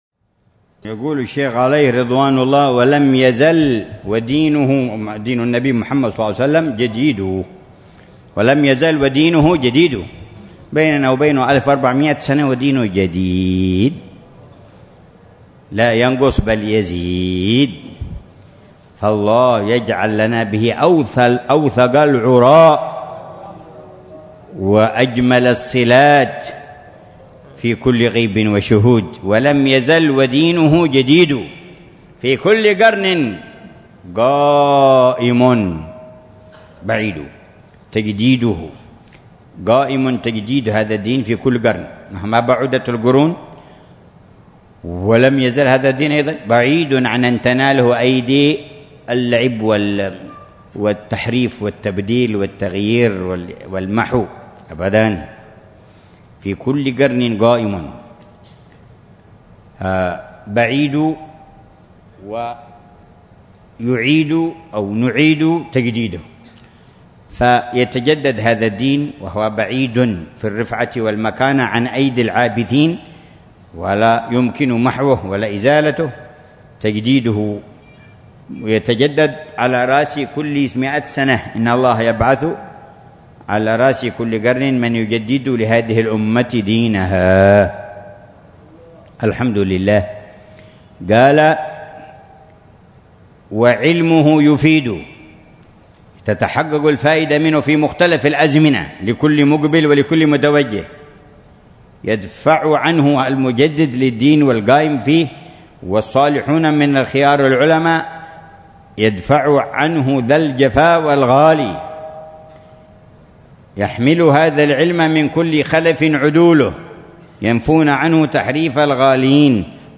شرح الحبيب عمر بن محمد بن حفيظ لرشفات أهل الكمال ونسمات أهل الوصال. تأليف السيد العلامة الإمام/ عبد الرحمن بن عبد الله بن أحمد بلفقيه (1089-1162هـ) بدار المصطفى ضمن دروس الدورة الصيفية الثالثة والعشرين من العام 1438هـ.